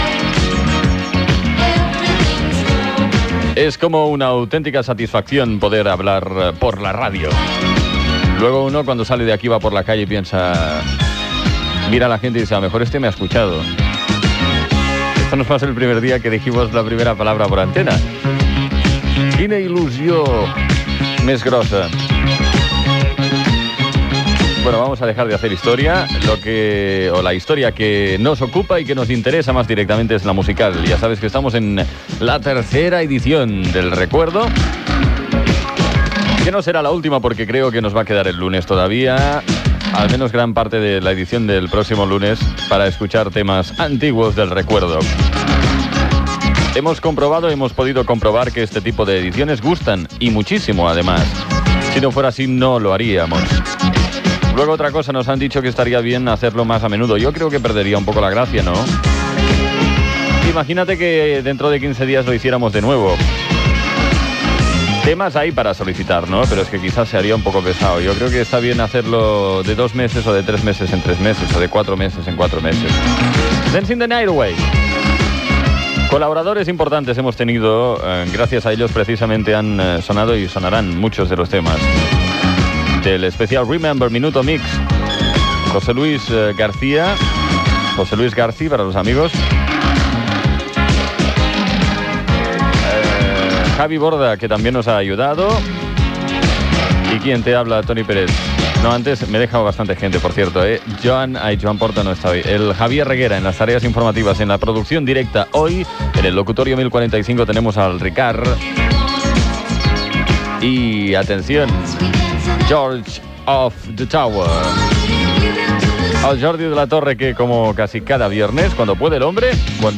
Musical
FM